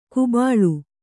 ♪ kubāḷu